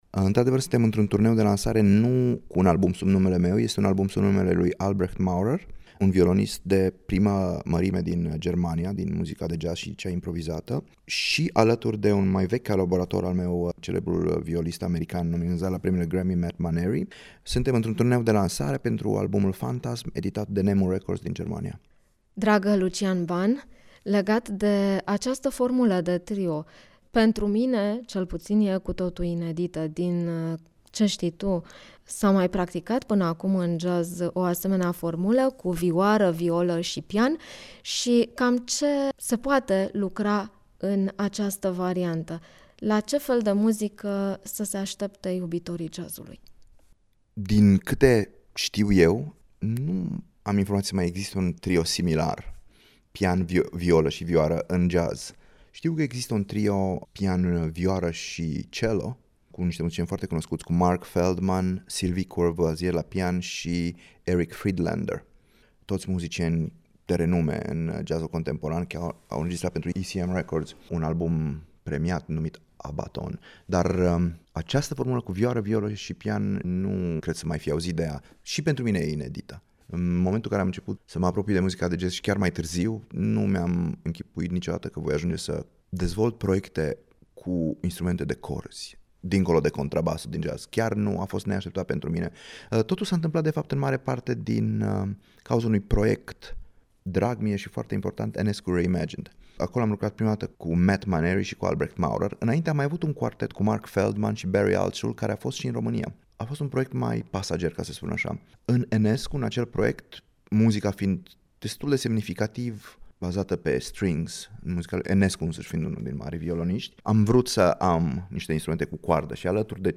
Secvență din interviul